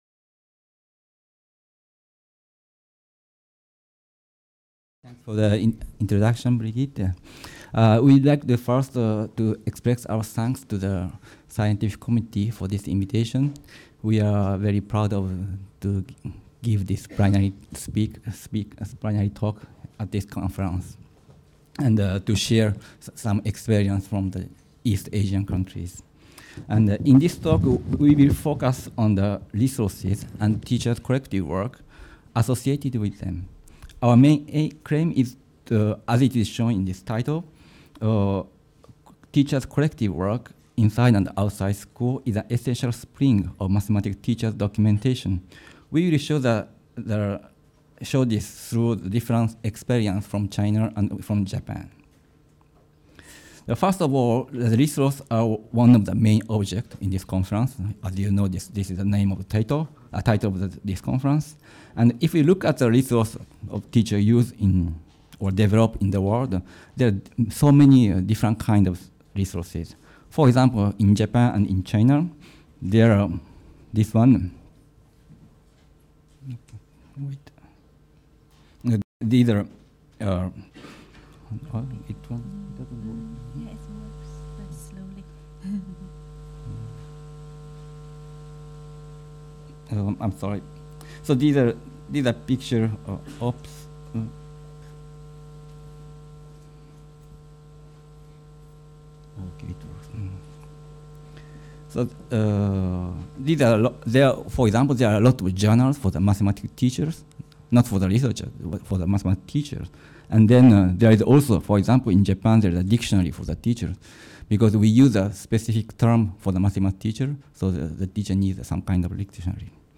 In this lecture, we try to show how teachers’ collective work promotes documentational works on the resources including teaching material, lesson plan, textbooks, etc. for developing, communicating, sharing, and disseminating teaching practice and knowledge, through two East Asian examples